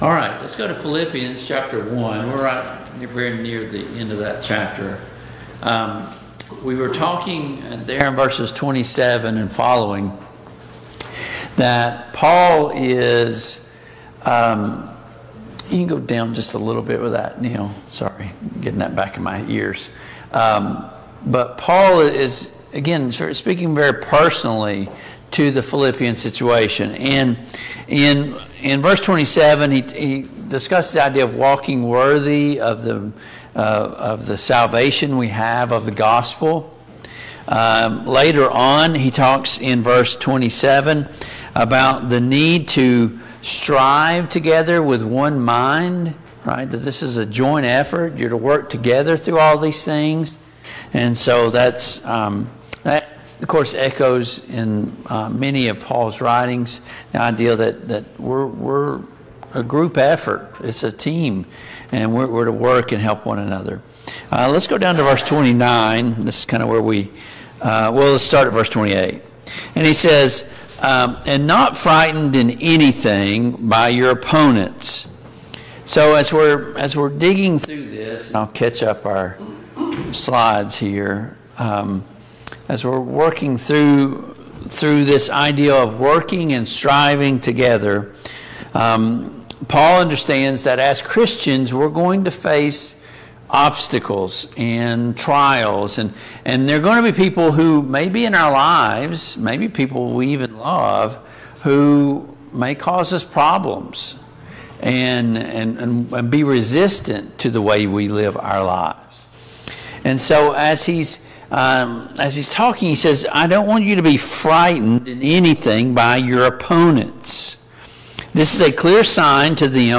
Passage: Philippians 1:27-30, Philippians 2:1-4 Service Type: Mid-Week Bible Study